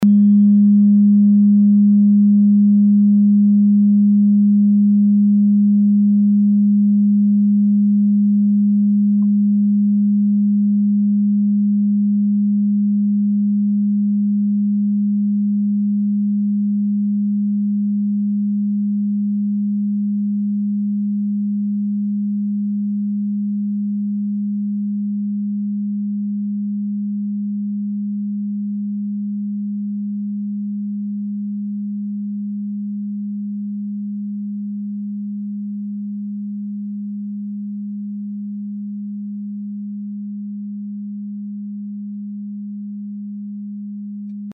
Klangschale TIBET Nr.5
Die Pi-Frequenz kann man bei 201,06 Hz hören. Sie liegt innerhalb unserer Tonleiter nahe beim "Gis".
klangschale-tibet-5.mp3